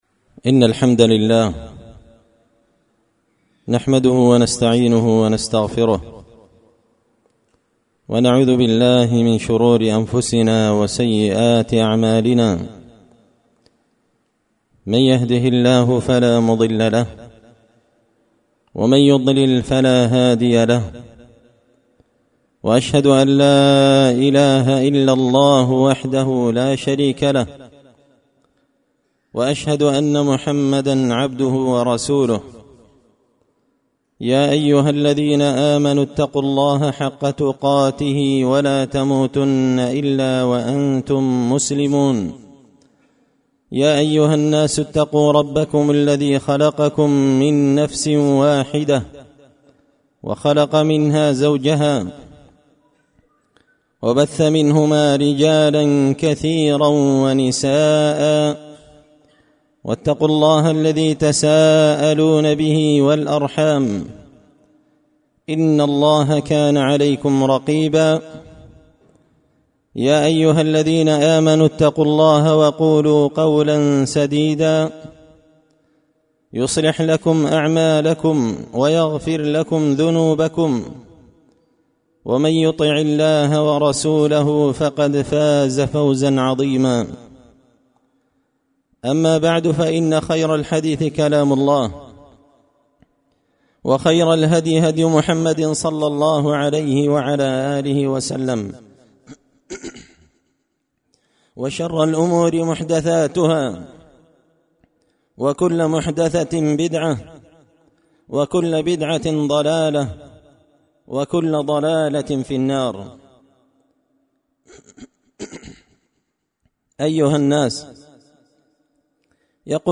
خطبة جمعة بعنوان
دار الحديث بمسجد الفرقان ـ قشن ـ المهرة ـ اليمن